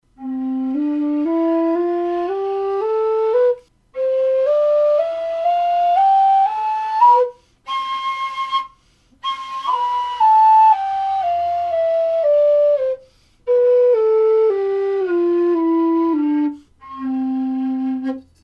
Лоу-вистл C (металл)
Лоу-вистл C (металл) Тональность: C
Модель вистла нижнего диапазона.